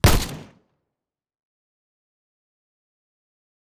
Assault Rifle Shot 2.wav